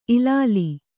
♪ ilāli